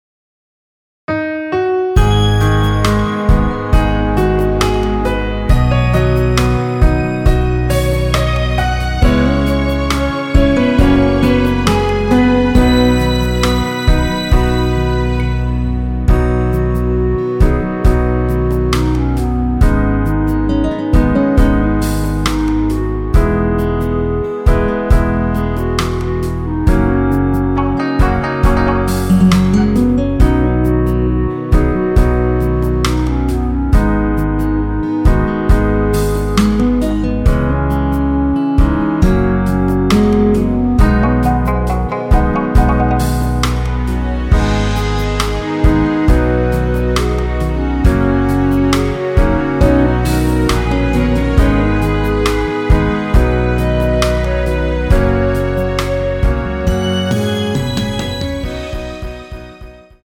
원키에서(-4)내린 멜로디 포함된 MR입니다.
앞부분30초, 뒷부분30초씩 편집해서 올려 드리고 있습니다.
중간에 음이 끈어지고 다시 나오는 이유는